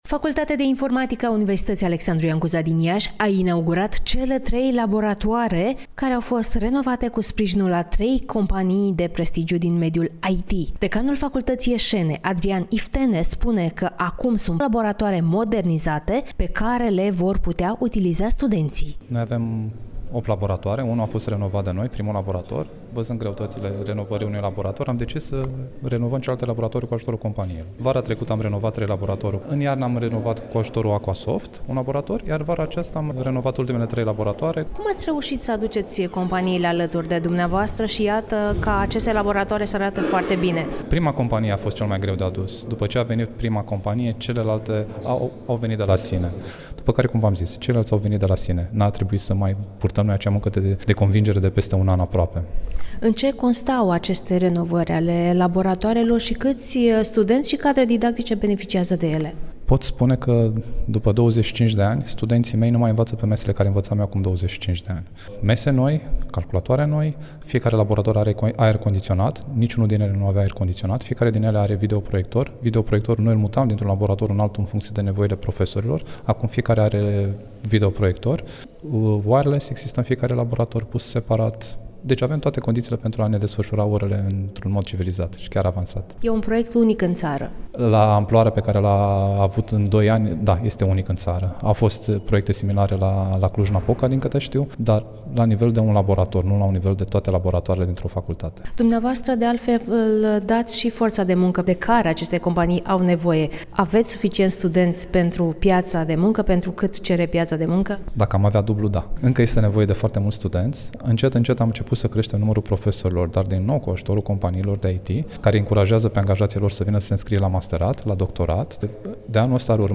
(INTERVIU) Proiect unic în țară la Facultatea de Informatică din Iași